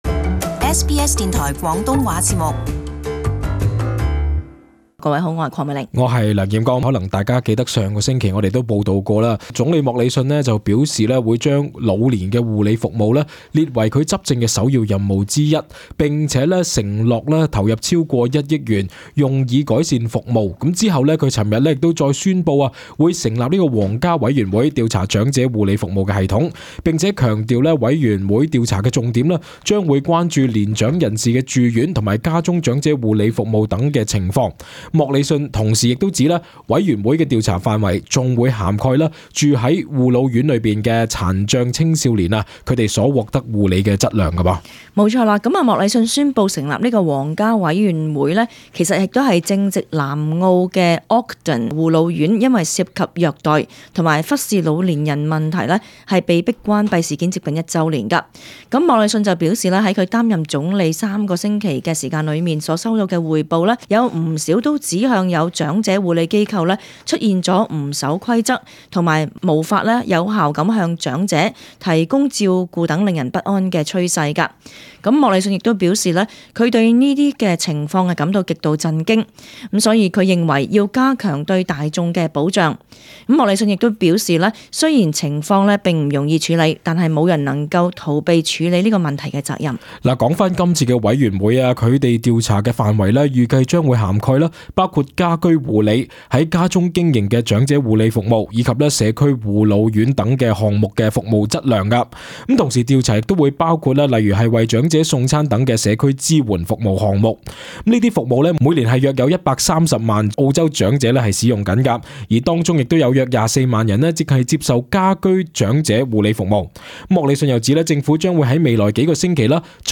【時事報導】總理宣布成立皇家委員會調查護老服務行業